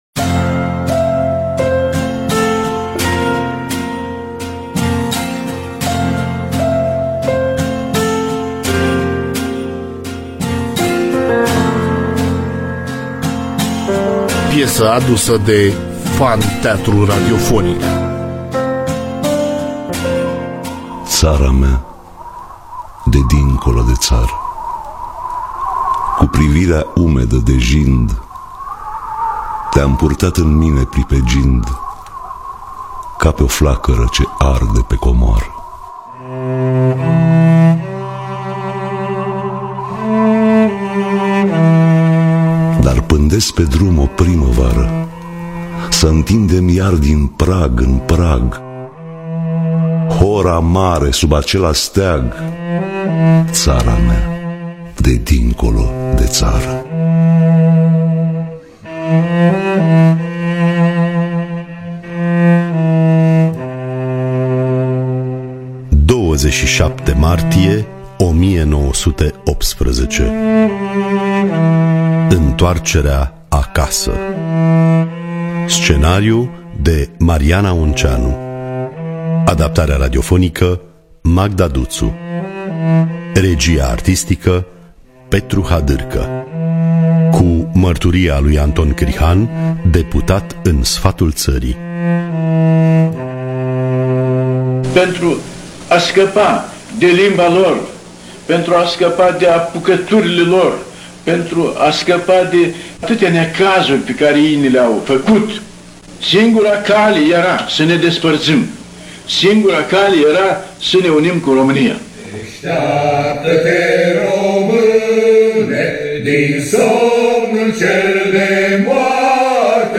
Biografii, Memorii: 27 Martie 1918 – Intoarcerea Acasa (2018) – Teatru Radiofonic Online